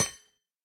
Minecraft Version Minecraft Version 1.21.5 Latest Release | Latest Snapshot 1.21.5 / assets / minecraft / sounds / block / copper_grate / step4.ogg Compare With Compare With Latest Release | Latest Snapshot
step4.ogg